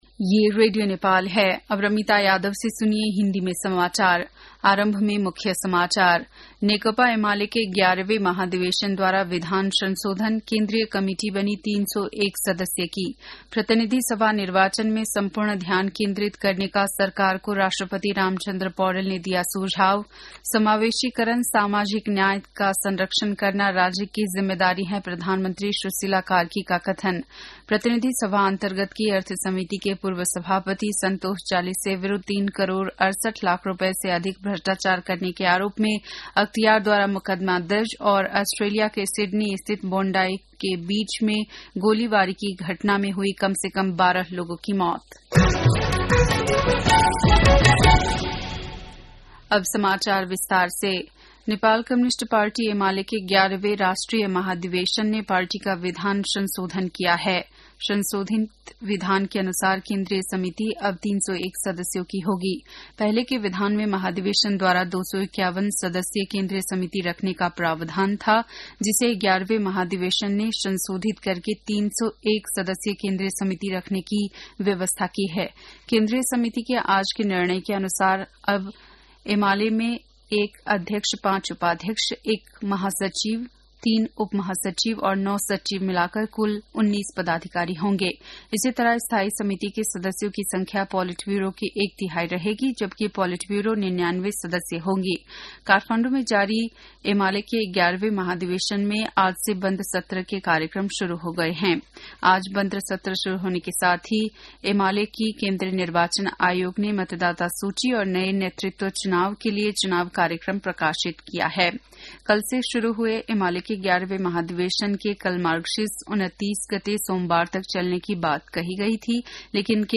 बेलुकी ९ बजेको नेपाली समाचार : २८ मंसिर , २०८२